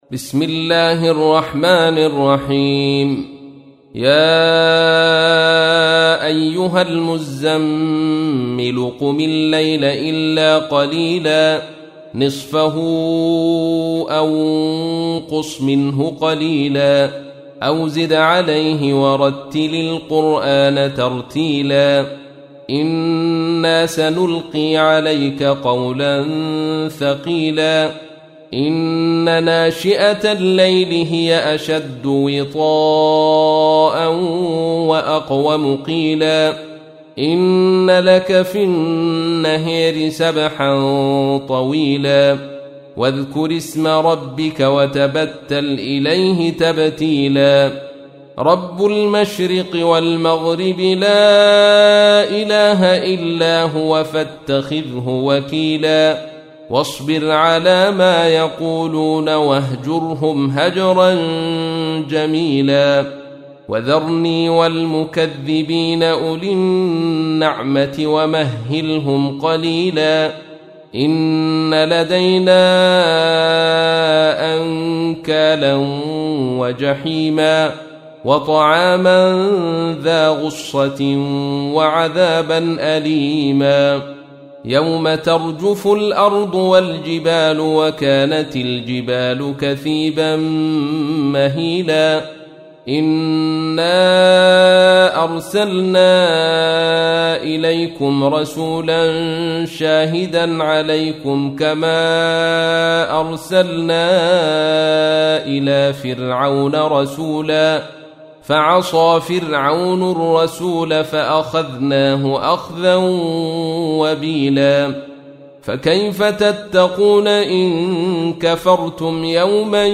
تحميل : 73. سورة المزمل / القارئ عبد الرشيد صوفي / القرآن الكريم / موقع يا حسين